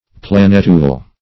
Planetule \Plan"et*ule\, n. A little planet.